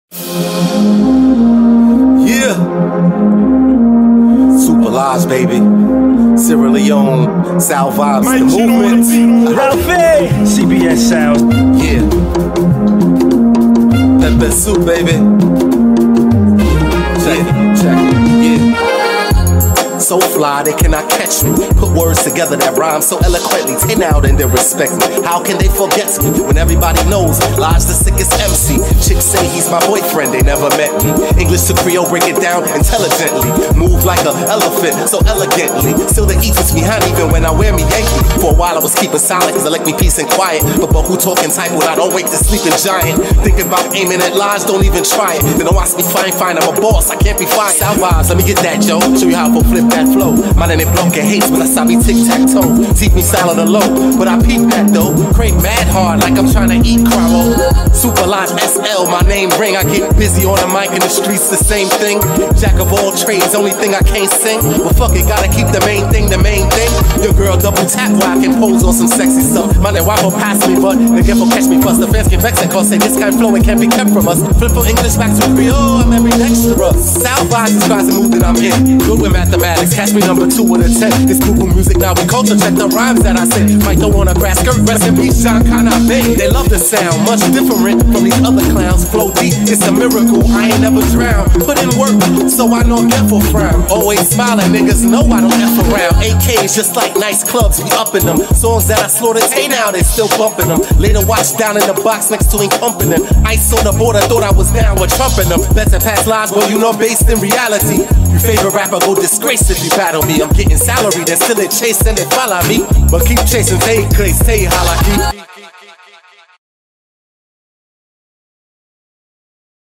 African rap artist and Sierra Leonean hip-hop pioneer
untouchable freestyle
came in strong with heavy bars and punchlines